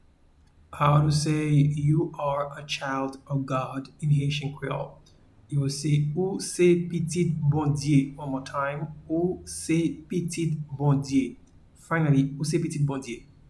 Pronunciation and Transcript:
an experienced Haitian audio translator and voice-over artist can be heard in the recording here: